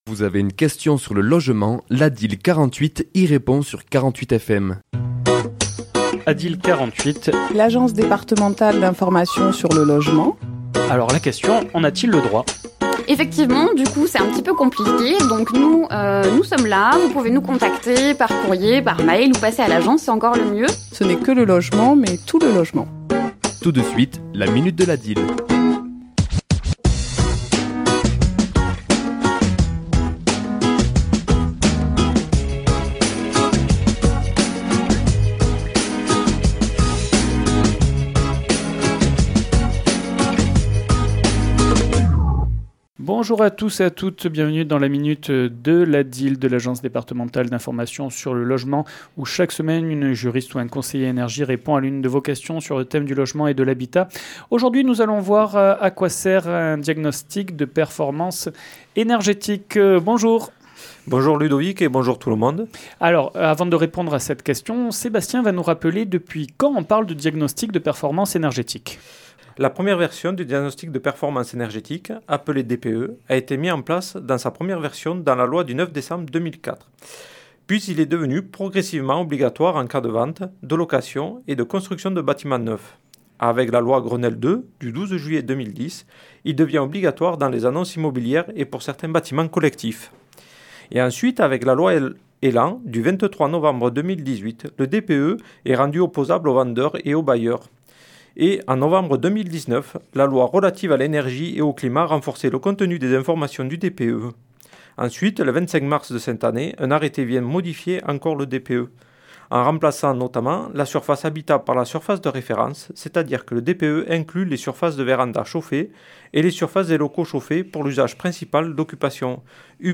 ChroniquesLa minute de l'ADIL
Chronique diffusée le mardi 31 décembre à 11h et 17h10